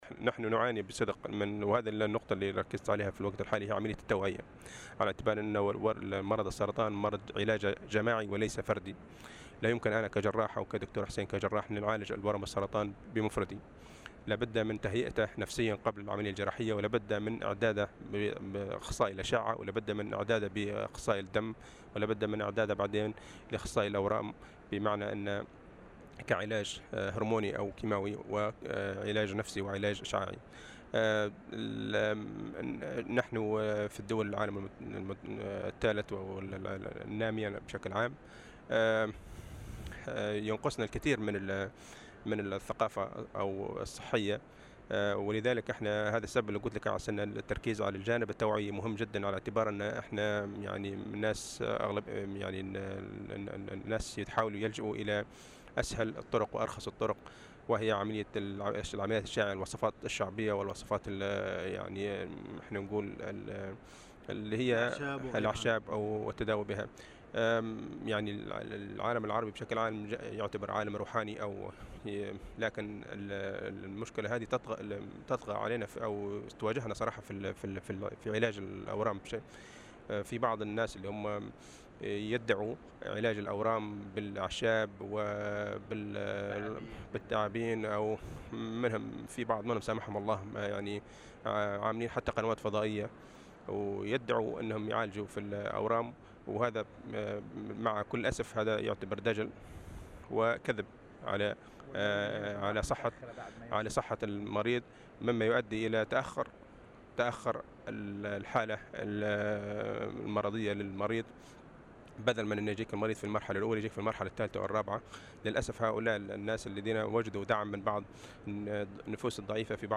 على هامش مشاركتهما في المؤتمر العالمي للسرطان المنعقد في جنيف